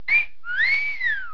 Whistle_1.wav